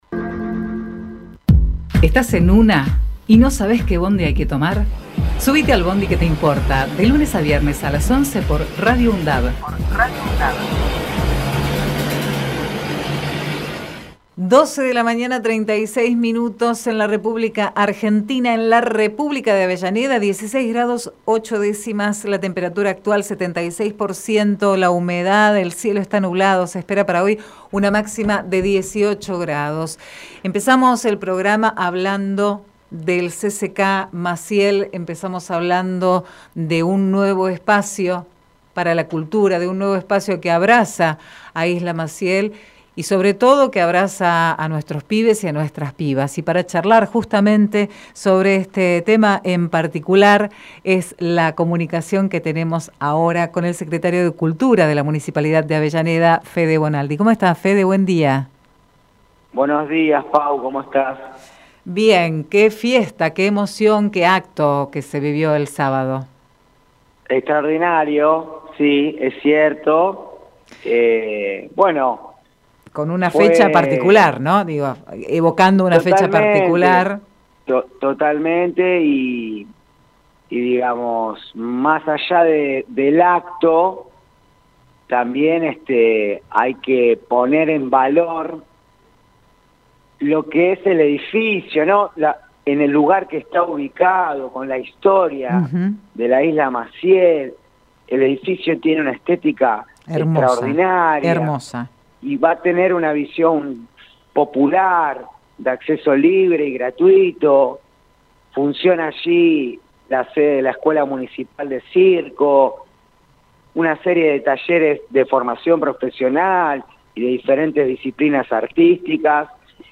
QUÉ TE IMPORTA - Federico Bonaldi Texto de la nota: Entrevista realizada en "Qué te Importa" a Federico Bonaldi, Secretario de Cultura de la Municipalidad de Avellaneda Archivo de audio: QUÉ TE IMPORTA - Federico Bonaldi Programa: Qué te importa?!